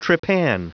Prononciation du mot trepan en anglais (fichier audio)
Prononciation du mot : trepan